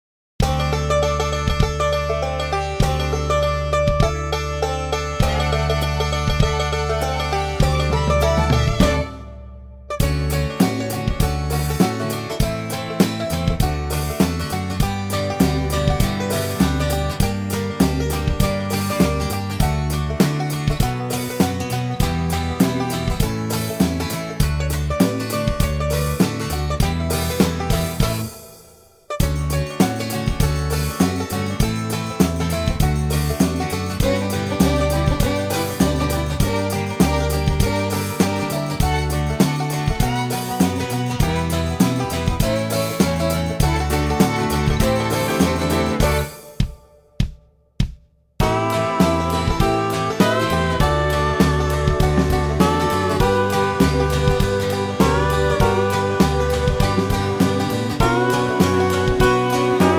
key D
4 bar intro (half time feel) and into the vocal at 9 seconds
key - D - vocal range - A to E (optional B & D falsetto)
backing track